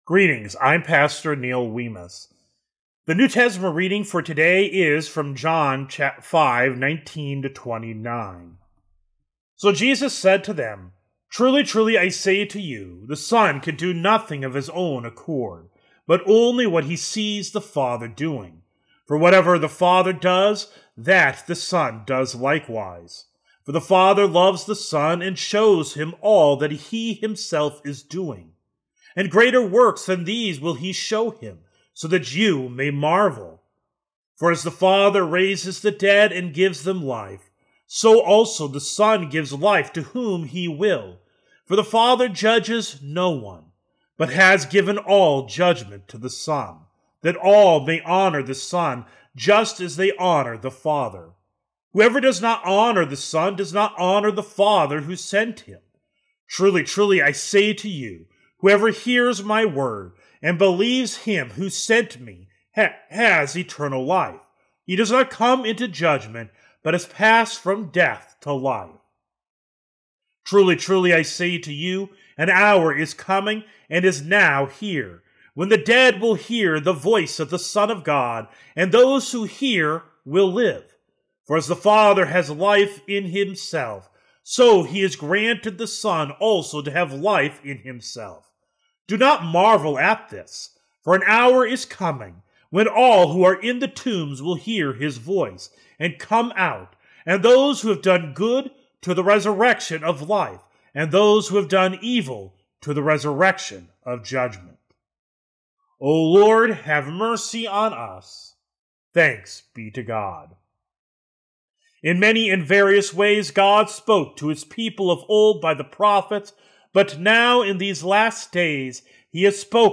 Morning Prayer Sermonette: John 5:19-29